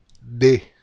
The following consonant is D. Nor has special rules and in the “flat” syllable sounds as follows: